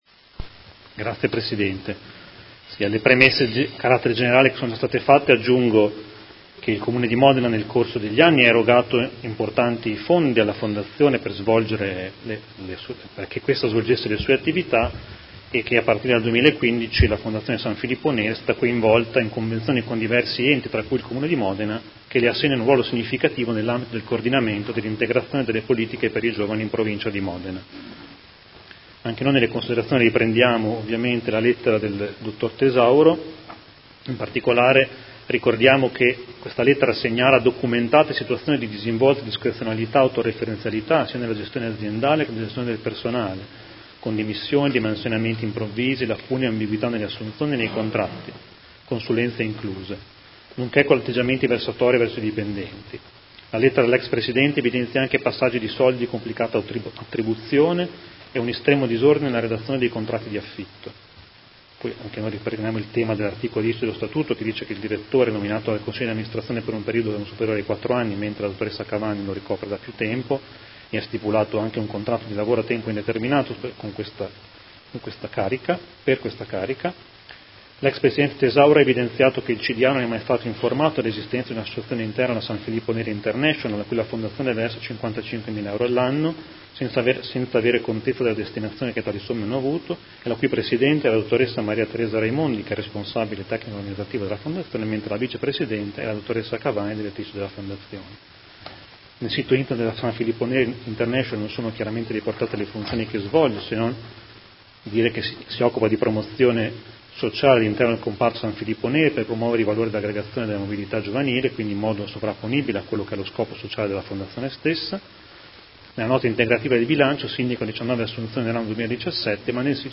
Seduta del 25/10/2018 Interrogazione del Gruppo Consiliare Movimento cinque Stelle avente per oggetto: San Filippo Neri